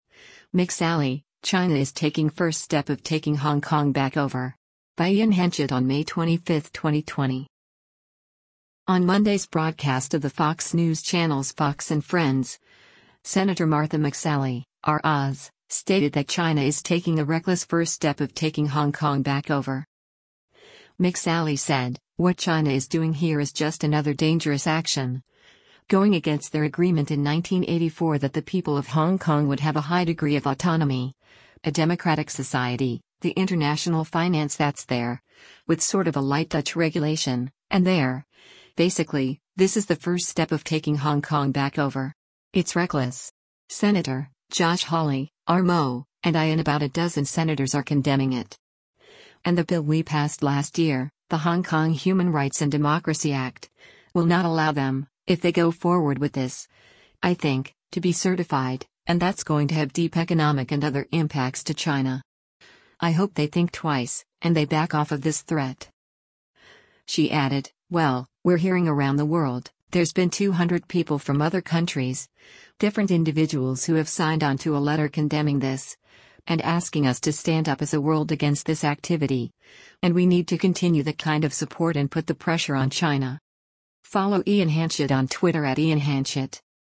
On Monday’s broadcast of the Fox News Channel’s “Fox & Friends,” Sen. Martha McSally (R-AZ) stated that China is taking a “reckless” “first step of taking Hong Kong back over.”